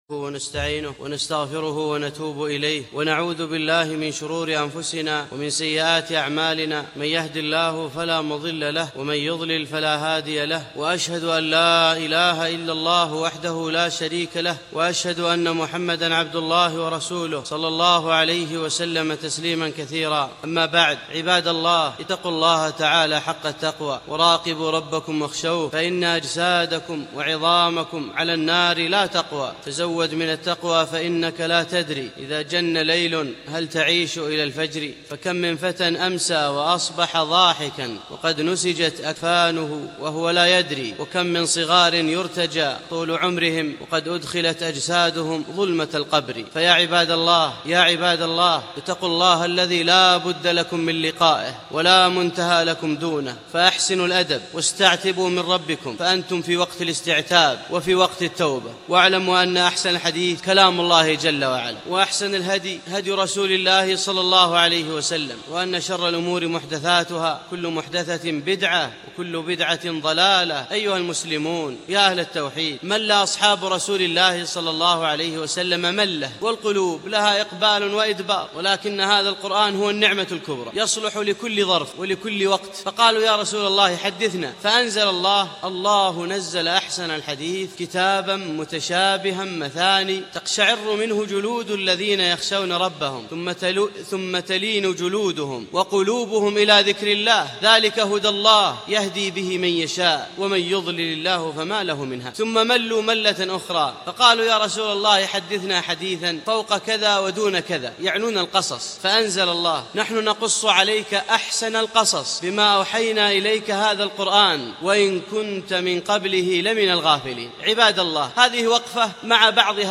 خطبة الجمعة بعنوان الهدهد داعية التوحيد